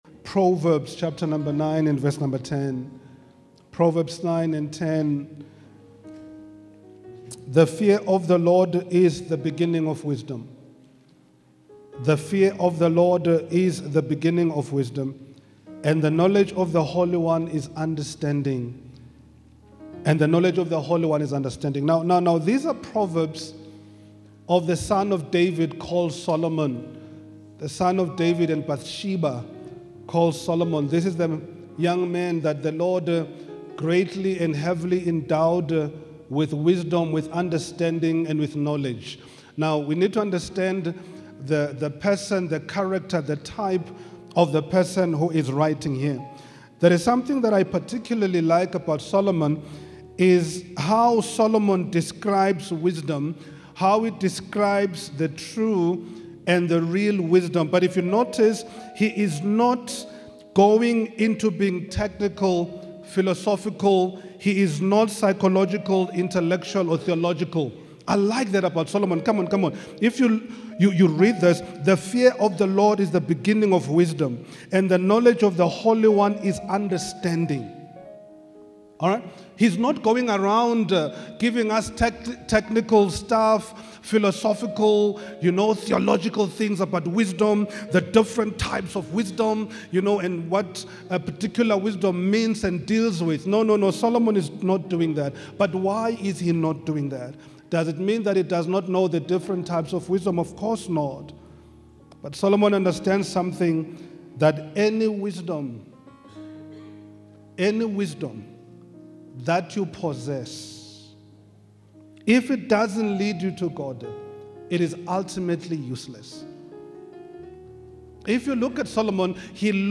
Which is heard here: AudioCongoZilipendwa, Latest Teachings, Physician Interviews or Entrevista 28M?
Latest Teachings